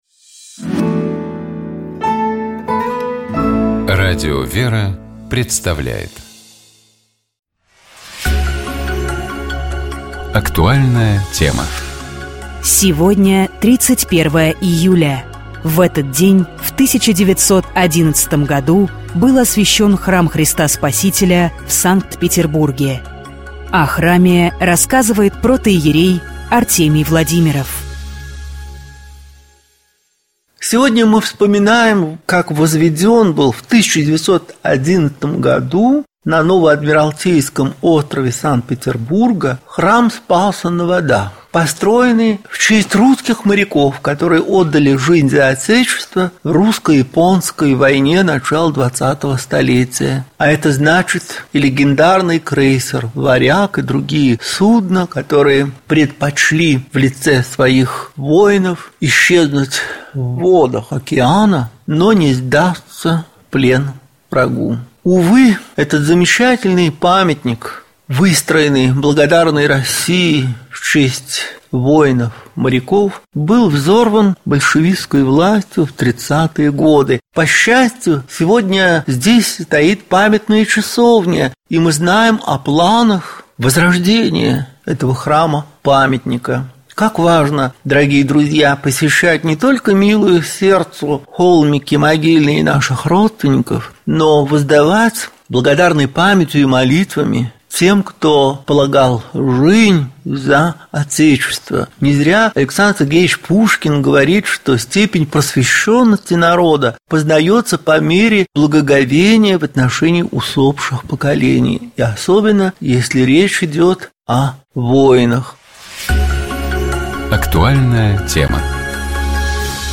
В этот день в 1911 году был освящён храм Христа Спасителя в Санкт-Петербурге. О храме рассказывает протоиерей